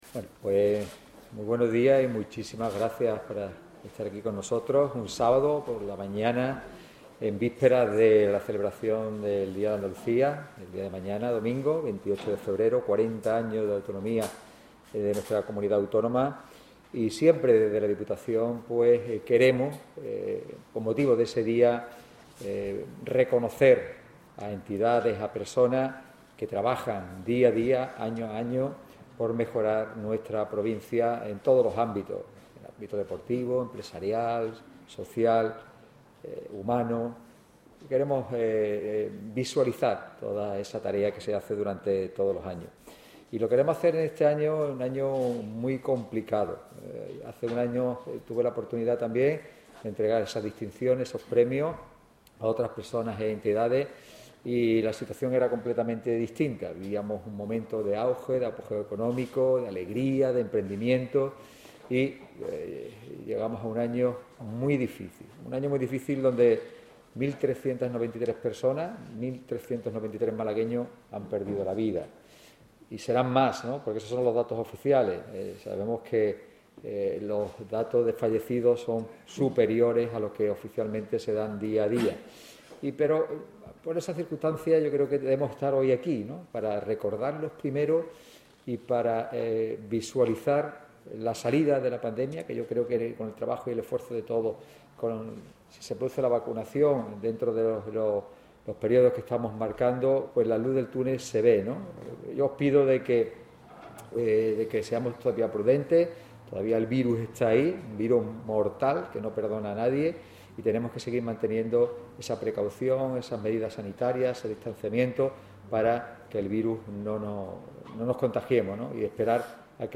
Discurso de Francis Salado, presidente de la Diputación
El auditorio Edgar Neville de la Diputación de Málaga (c/Pacífico, 54) ha acogido hoy sábado, 27 de febrero, la celebración del Día de Andalucía con la entrega de las distinciones ‘‘M de Málaga’’.